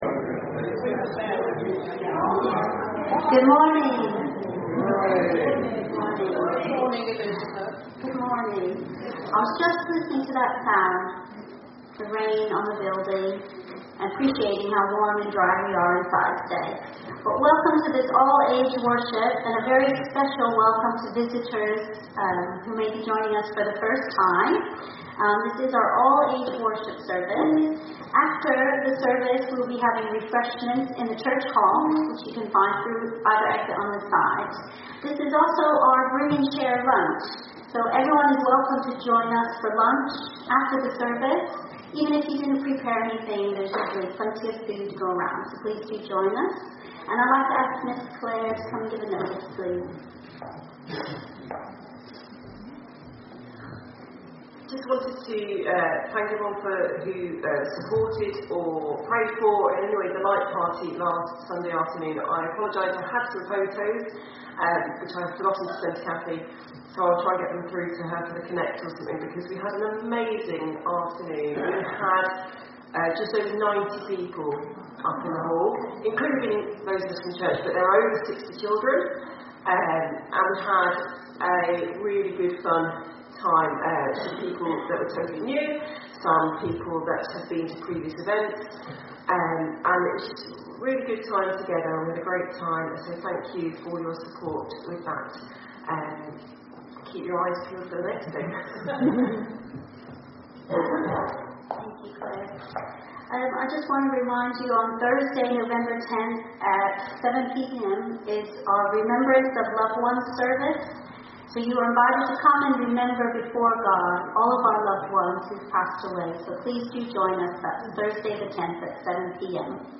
An audio version of the service is also available.
Service Type: All Age Worship